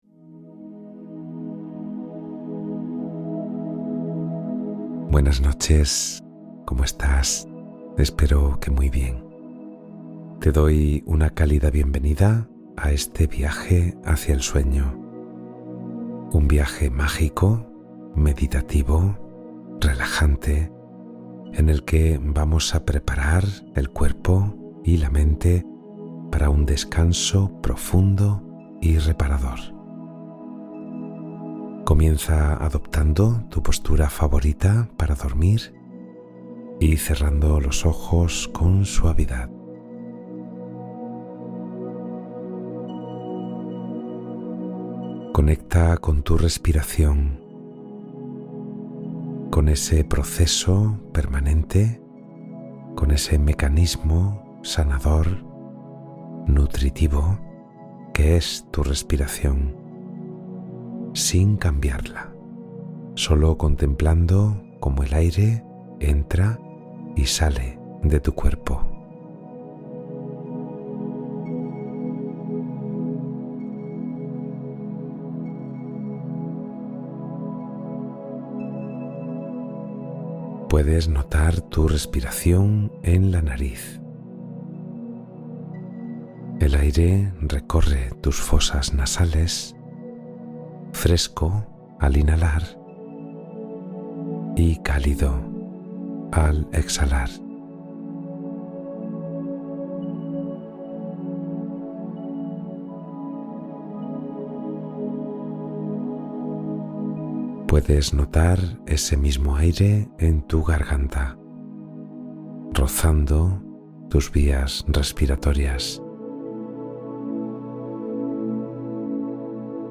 Cuento Guiado para Dormir con Paz y Serenidad